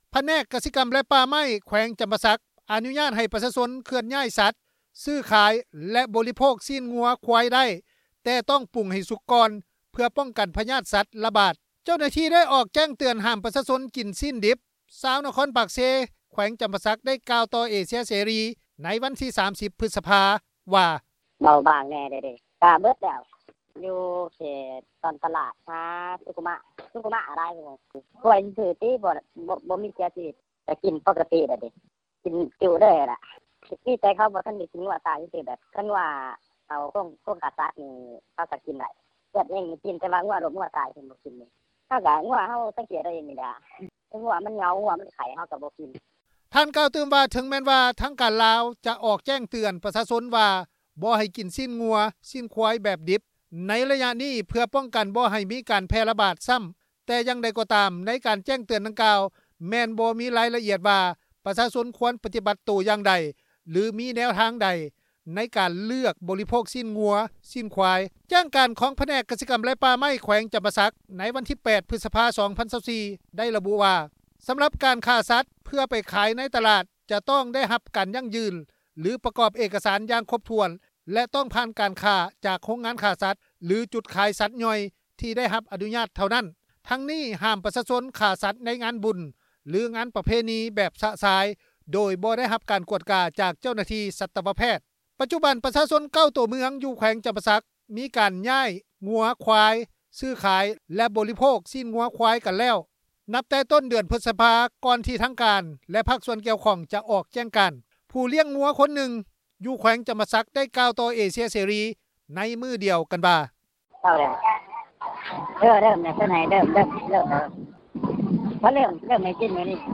ຜູ້ທີ່ລ້ຽງງົວ ຄົນໜຶ່ງ ຢູ່ແຂວງຈໍາປາສັກ ໄດ້ກ່າວຕໍ່ວິທຍຸເອເຊັຽເສຣີ ໃນມື້ດຽວກັນວ່າ: